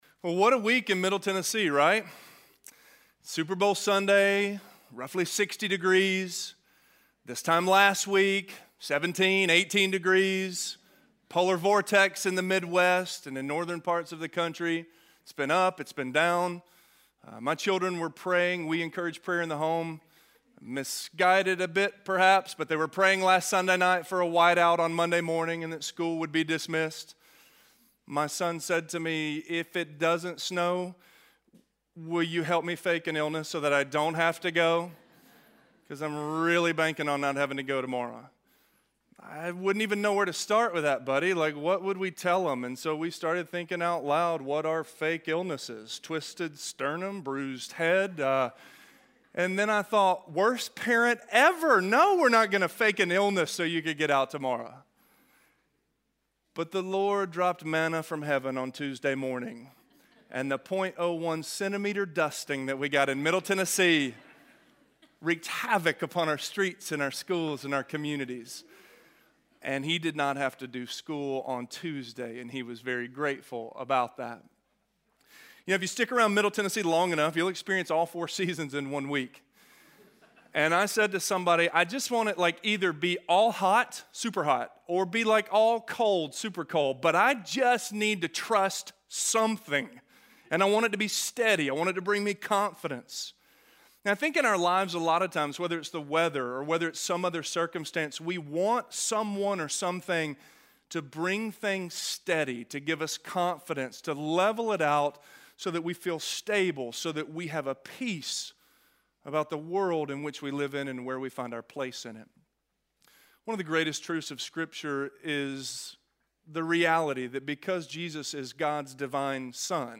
Authority - Sermon - Avenue South